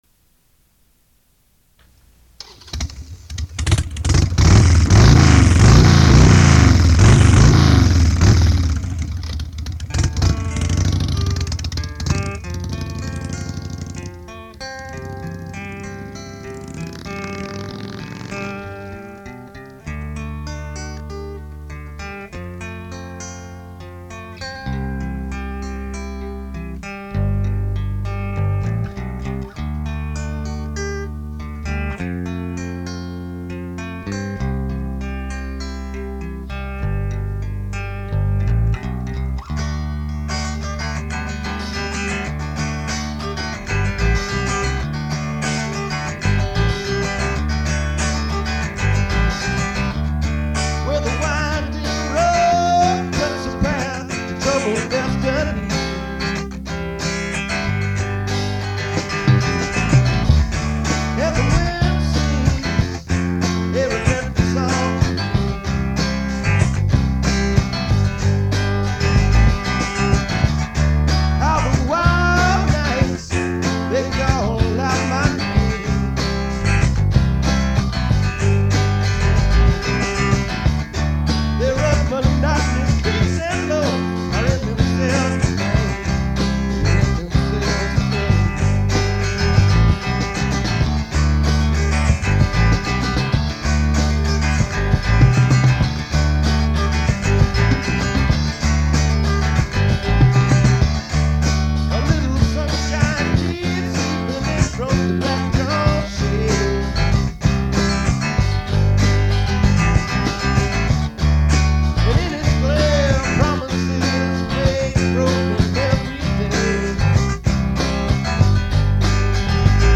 Country
Folk
Country-rock